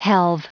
Prononciation du mot helve en anglais (fichier audio)
Prononciation du mot : helve